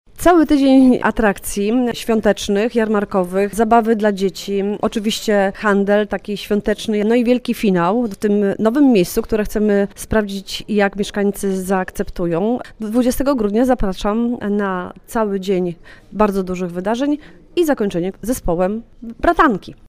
Joanna Agatowska, Prezydent Miasta Świnoujście:
SWIN-Agatowska-Jarmark.mp3